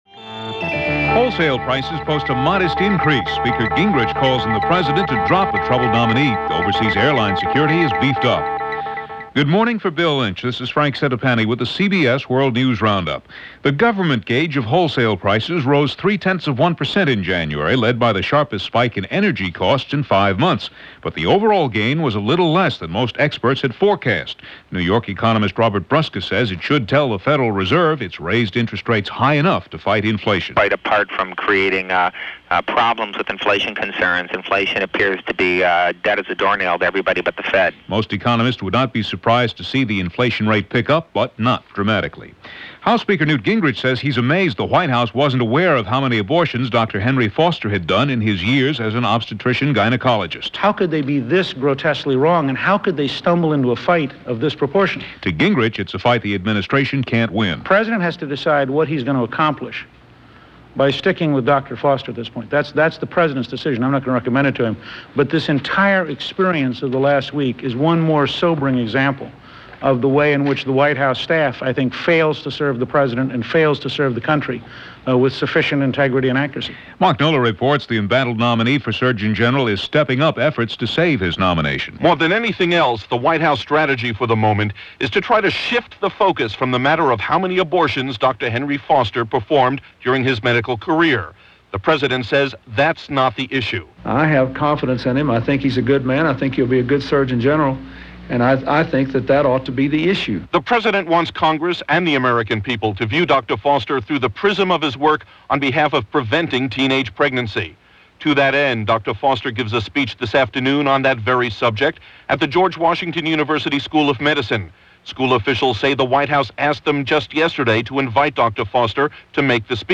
And that’s a lot of what went on today, this February 10th in 1995, as reported by The CBS World News Roundup.